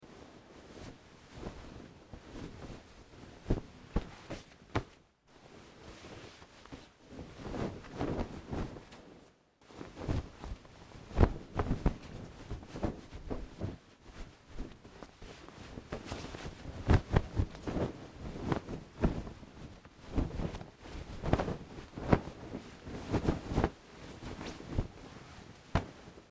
На этой странице собраны звуки, связанные с одеялом: шуршание ткани, легкое движение, уютное тепло.
Звук: укладываем одеяло спать